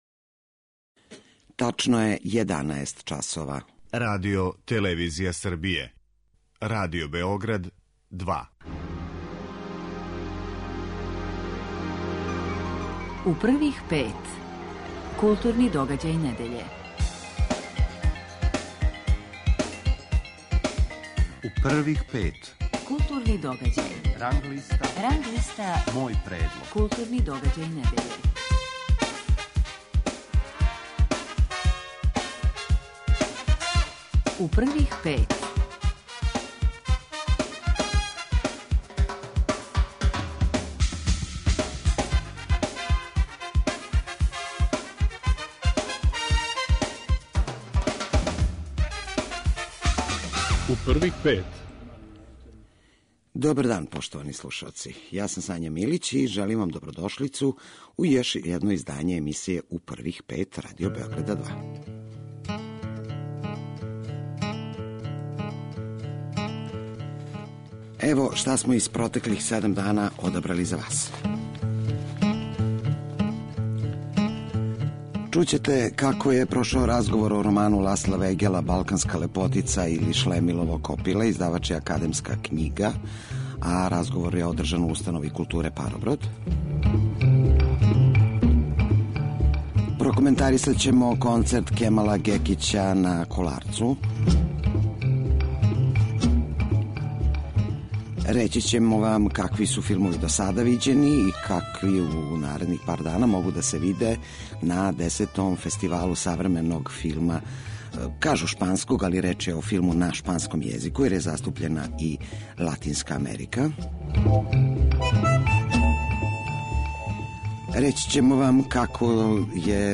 Гост емисије биће писац, академик Миро Вуксановић.